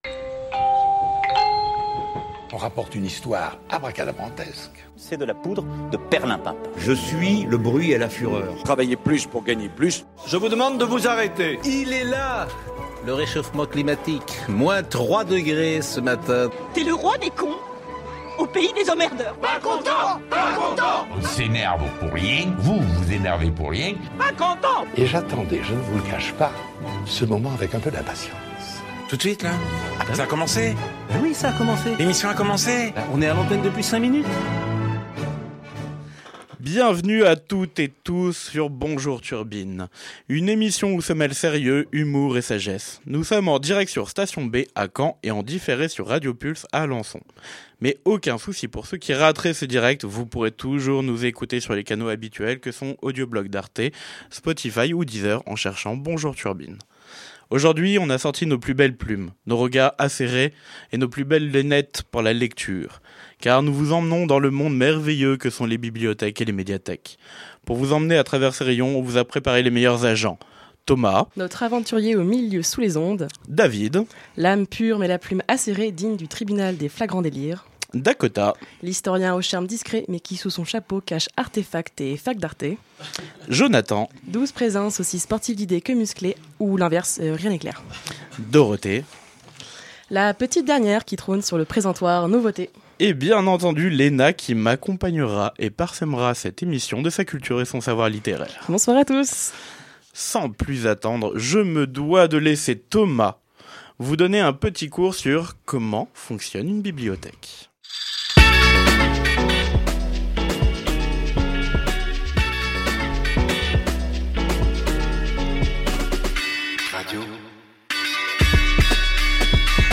Les échanges autour de cette émission sont riches, d’aventures, de rires et de souvenirs.